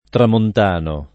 tramontano [ tramont # no ]